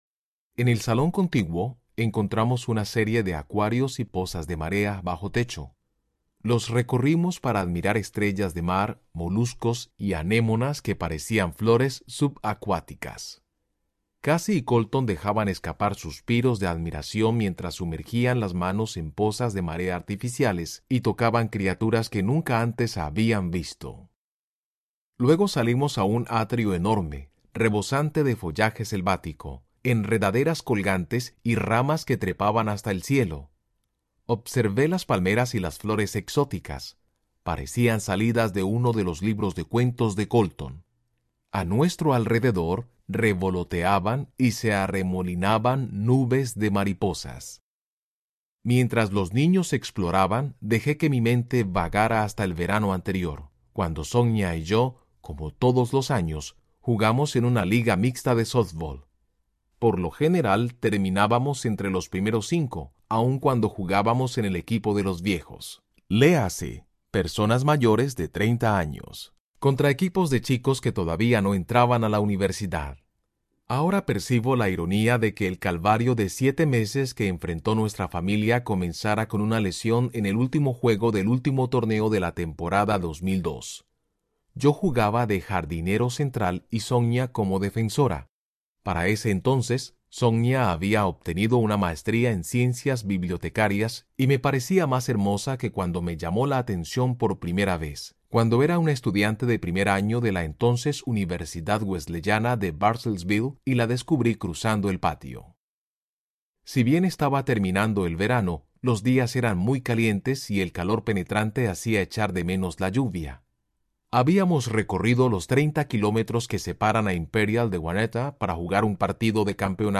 El cielo es real Audiobook
Narrator
4.6 Hrs. – Unabridged